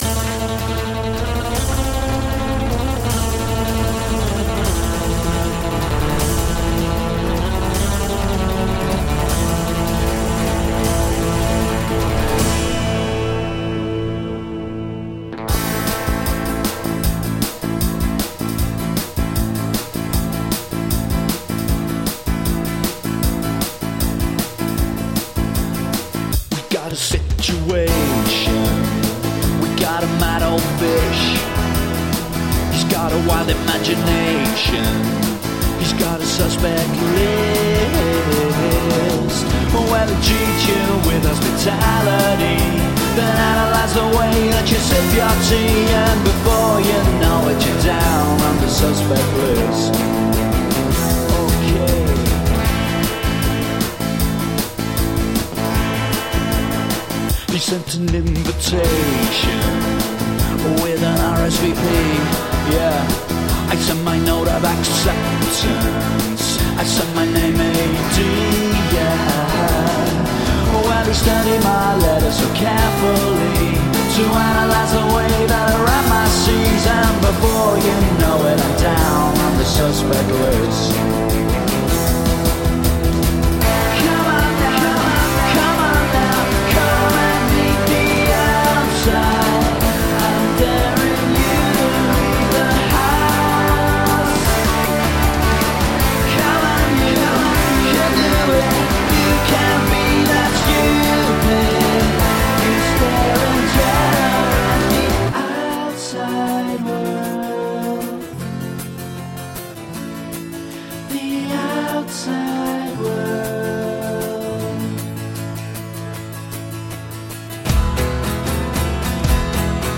Shimmering indie guitar pop with orchestral moments.
Tagged as: Alt Rock, Pop, Folk-Rock, Vocal, Electric Guitar